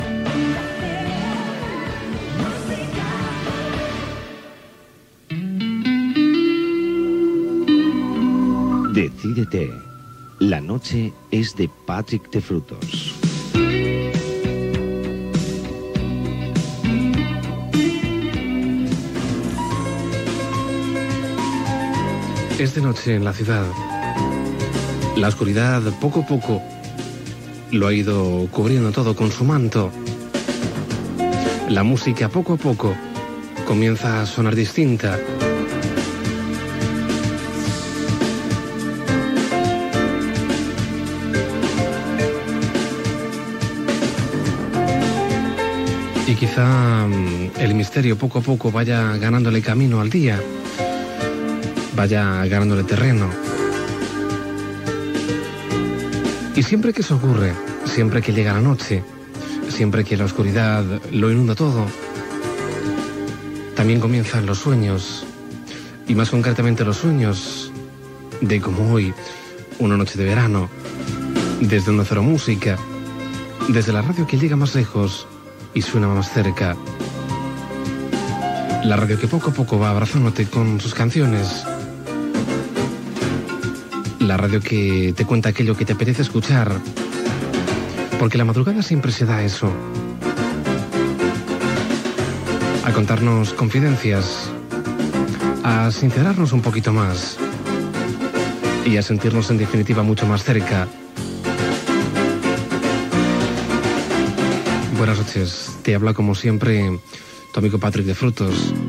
Inici d'un torn musical.
Musical
FM